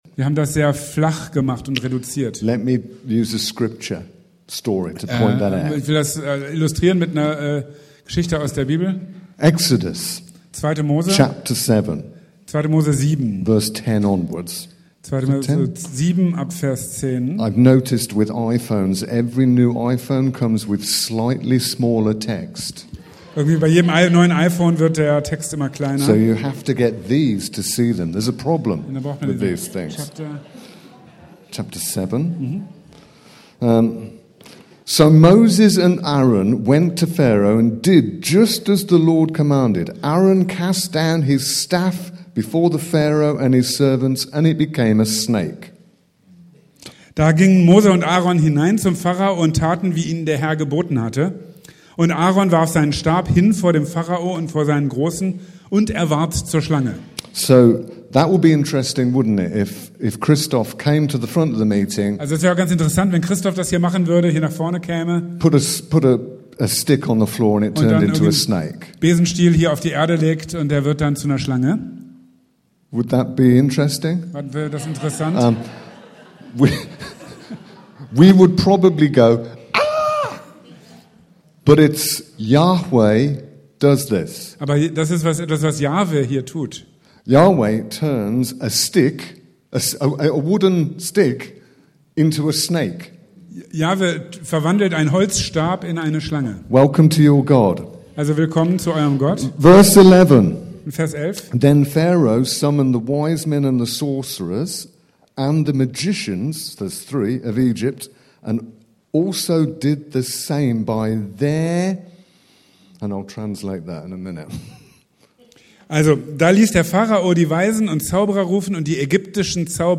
Leider fehlen die ersten ca. 2 Minuten